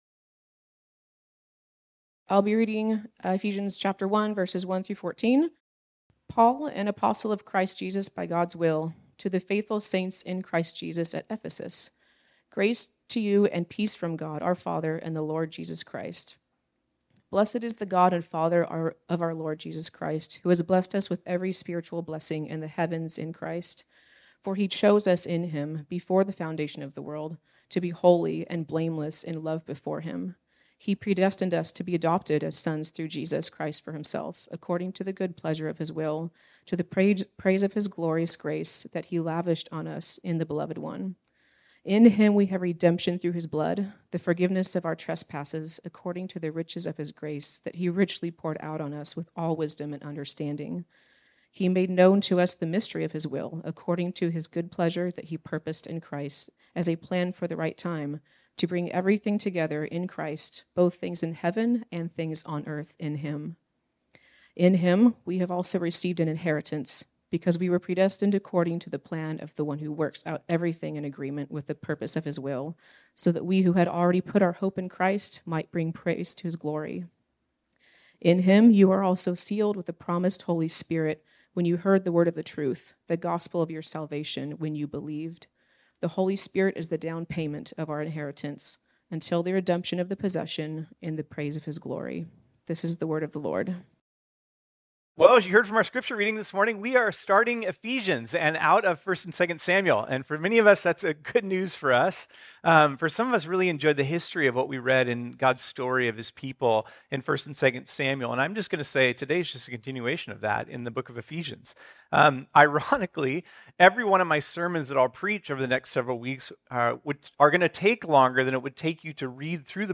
This sermon was originally preached on Sunday, September 10, 2023.